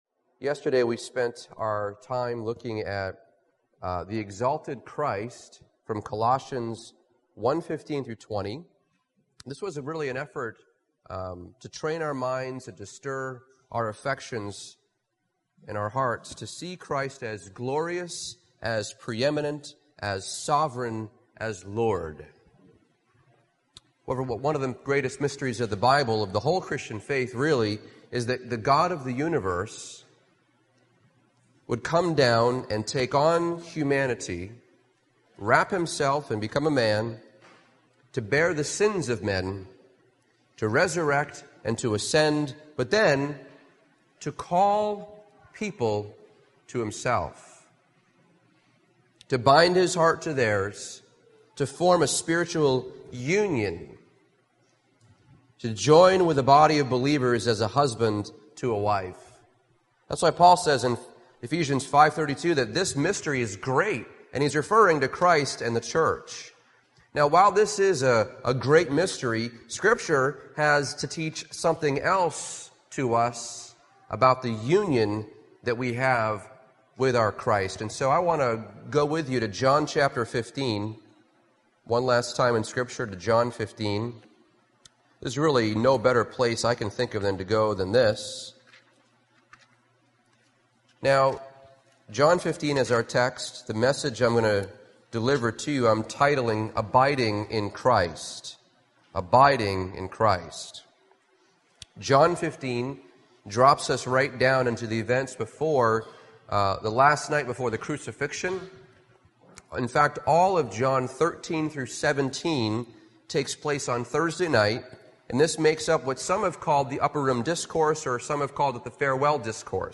2019 Category: Full Sermons Jesus Christ is the True Vine for us to abide in and bear fruit.